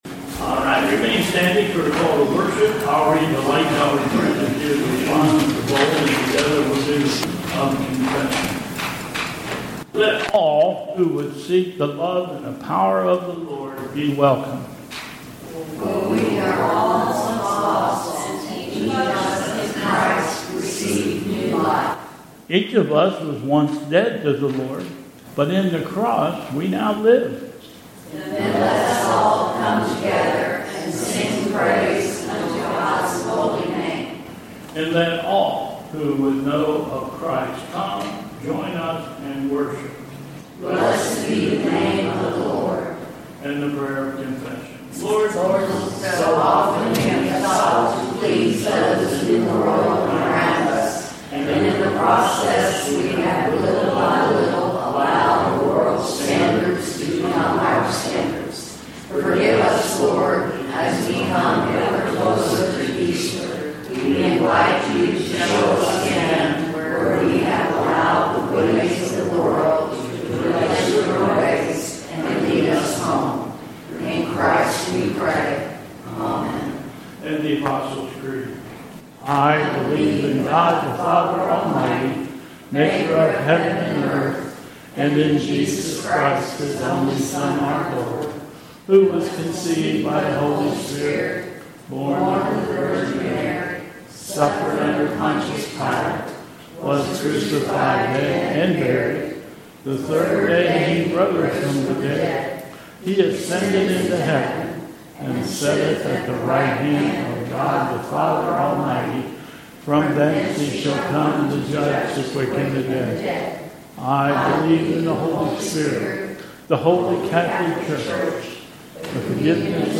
Bethel Church Service
Hymn: " Lord, I Lift Your Name on High"